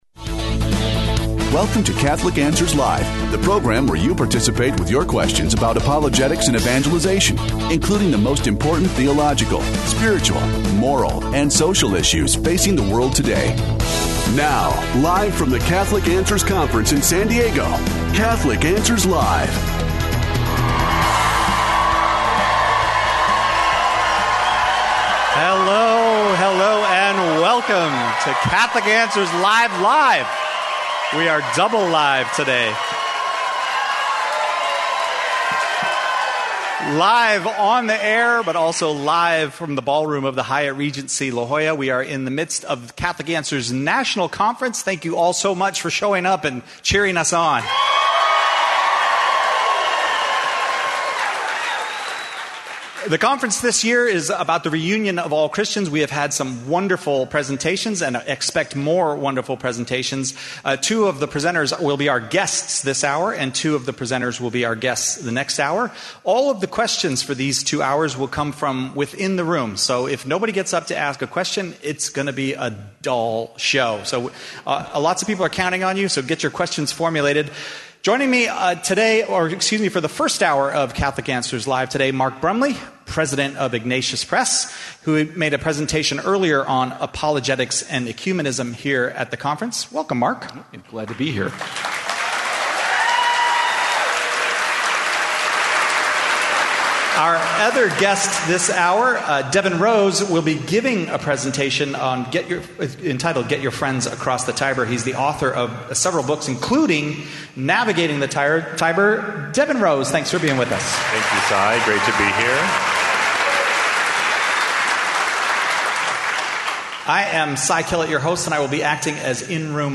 Live from the Catholic Answers National Conference, our guests consider the way forward toward Christian unity.